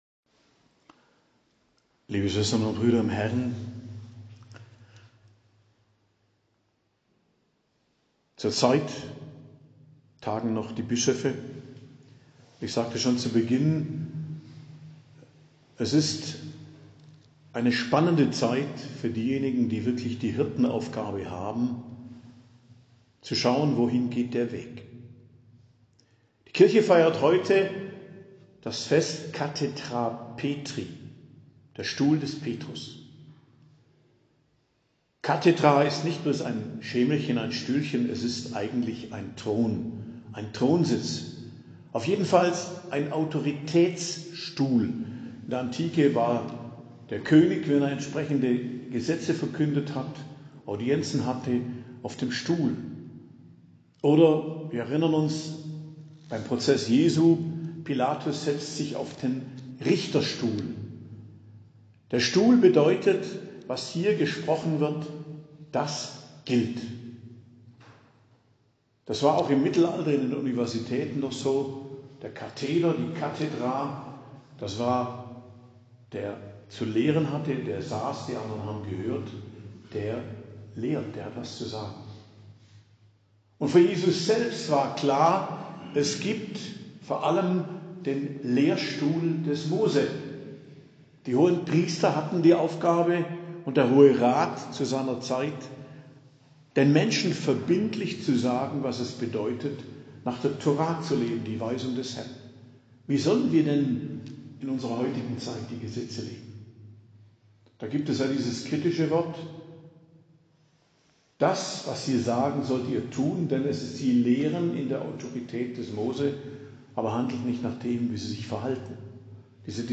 Predigt am Fest Kathedra Petri, 22.02.2024